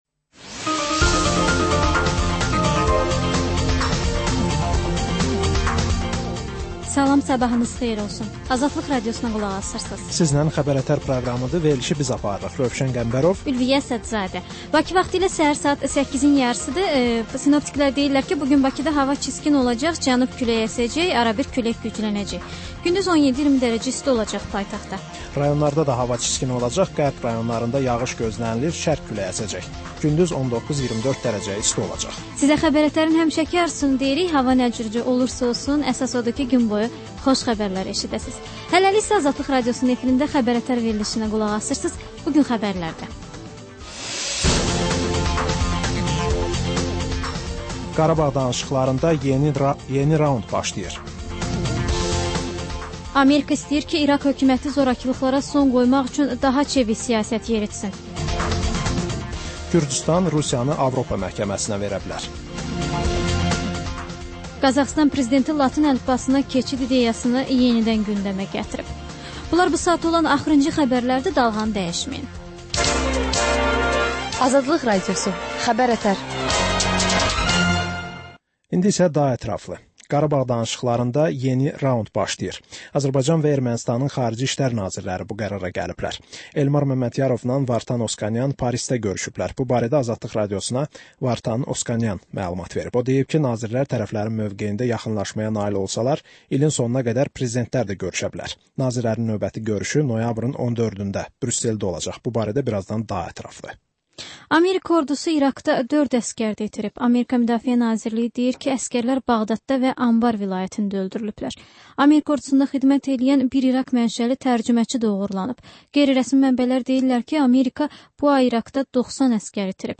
Səhər-səhər, Xəbər-ətərş Xəbərlər, reportajlar, müsahibələr